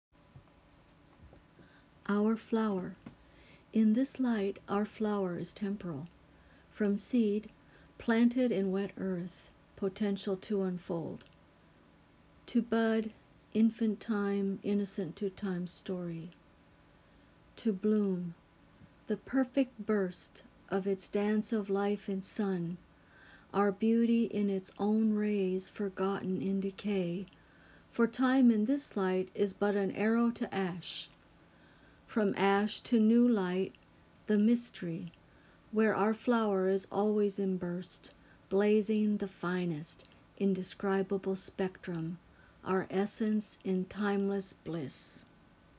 Recitation is good too.